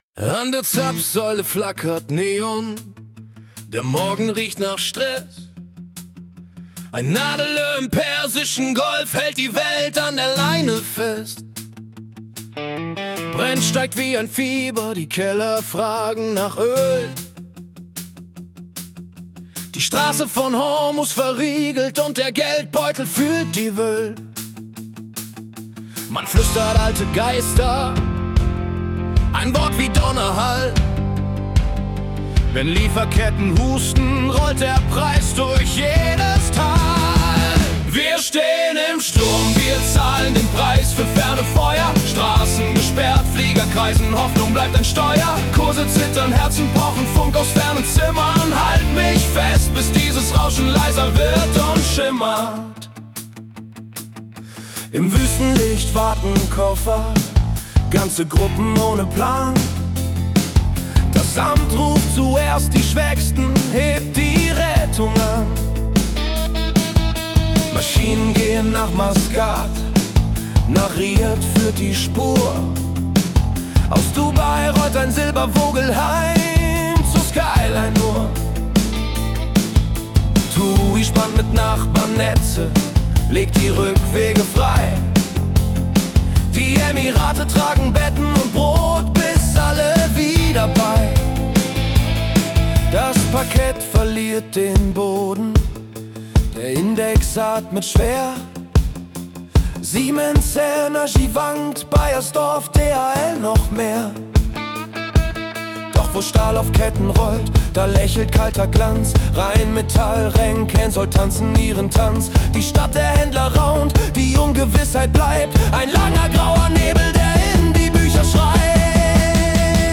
Die Nachrichten vom 6. März 2026 als Rock-Song interpretiert.
Jede Folge verwandelt die letzten 24 Stunden weltweiter Ereignisse in eine mitreißende Rock-Hymne. Erlebe die Geschichten der Welt mit fetzigen Riffs und kraftvollen Texten, die Journalismus...